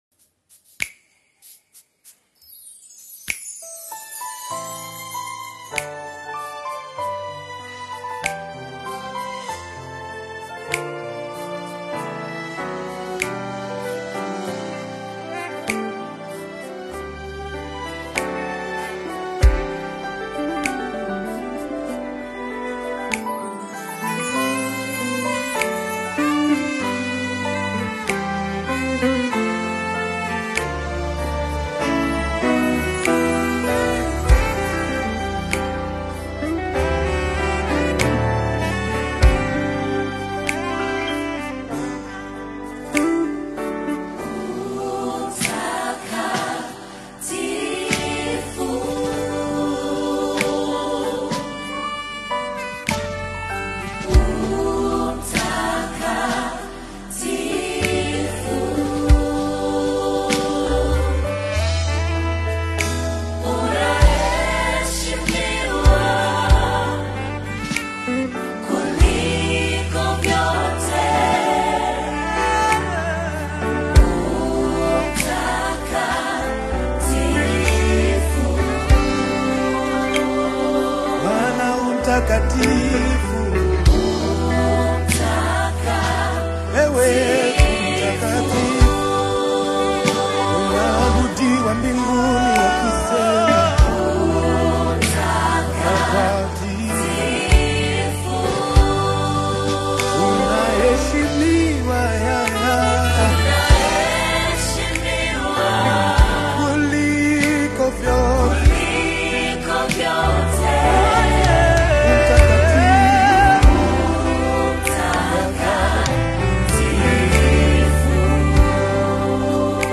Tanzanian gospel single